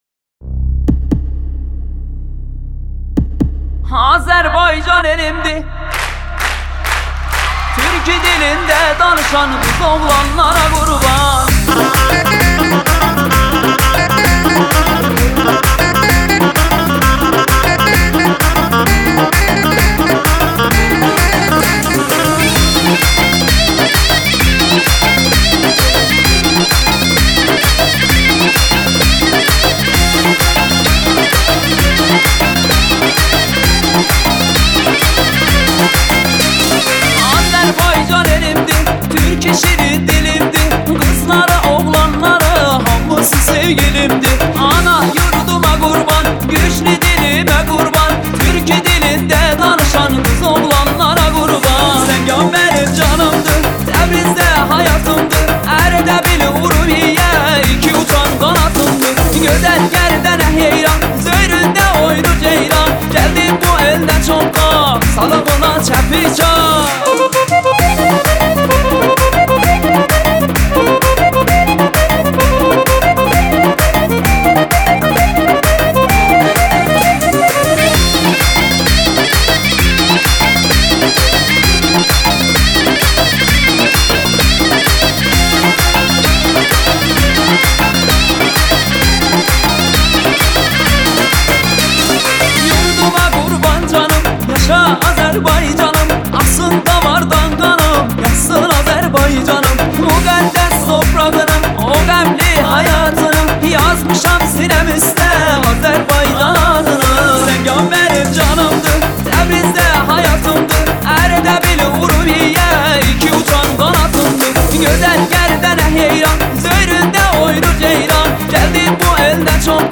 آهنگ آذری ترکی